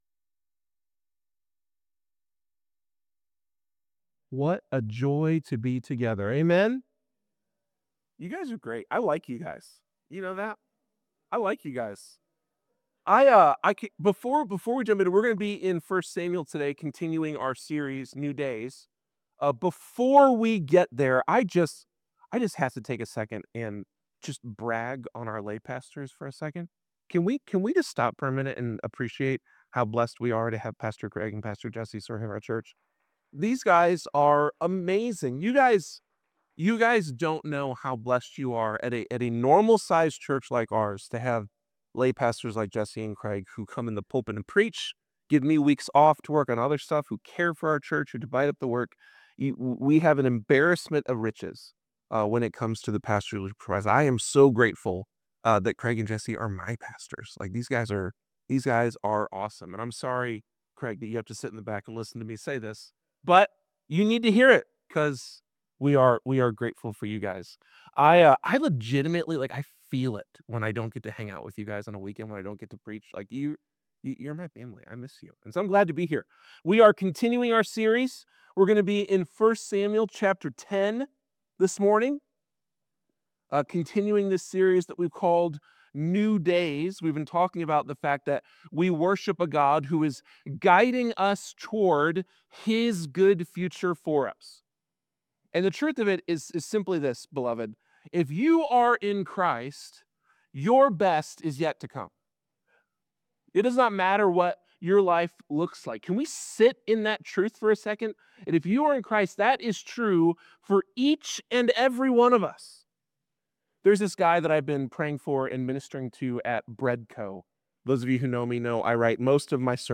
This sermon offers hope for anyone who feels like they've messed up God's plan, reminding us that through Christ, our best days a